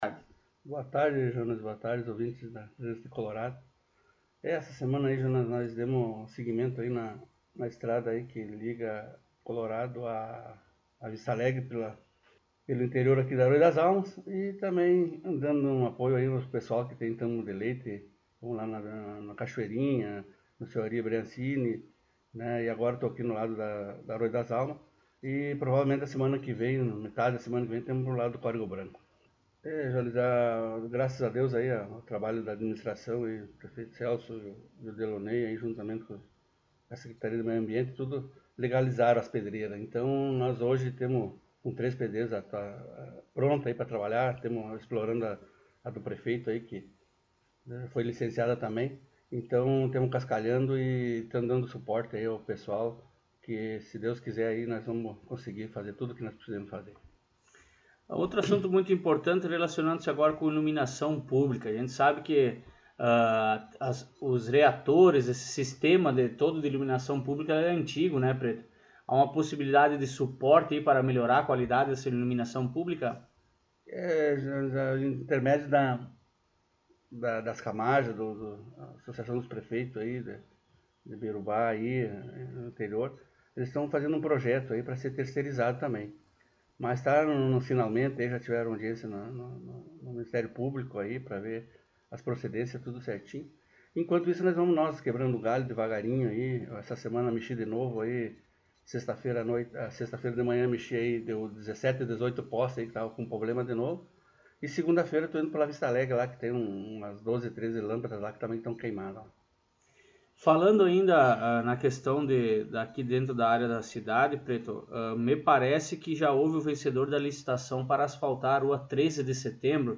Secretário Municipal de Obras concedeu entrevista